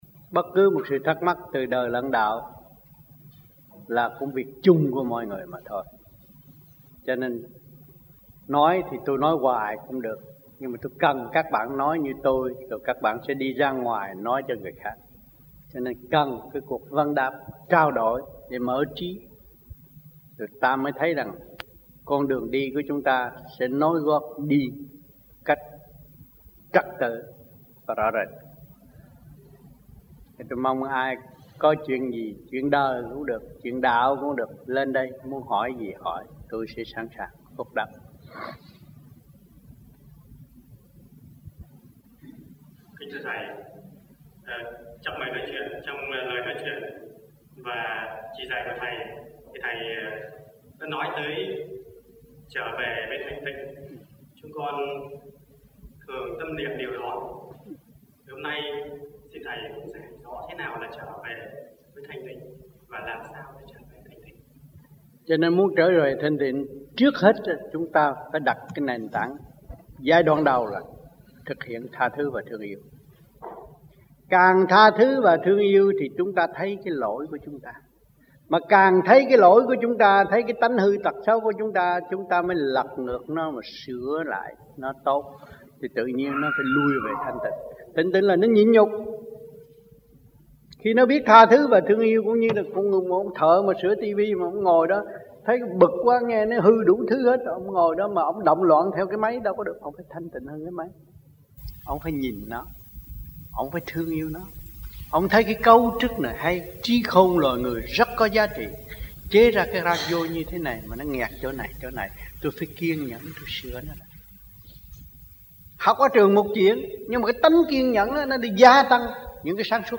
1990-05-05 - PERTH - LUẬN ĐẠO 1